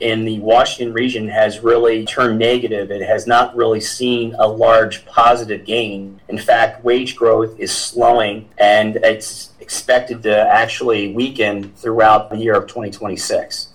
During a recent legislative update with Cumberland’s Mayor and City Council